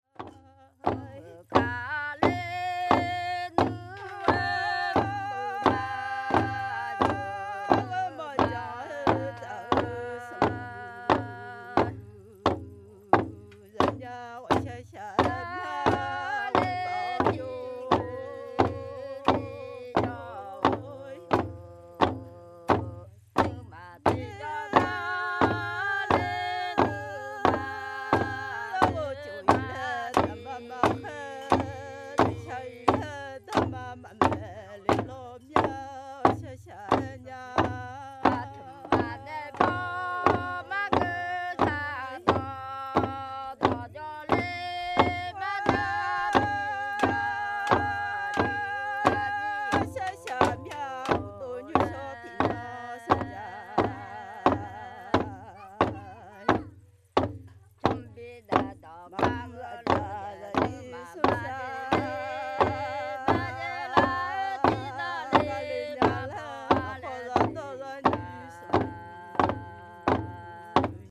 Akha music
work song with bamboo beaters the two women sing almost independently; the words go something like "my husband has left me, I feel sad and lonely and I want another one." 1.1MB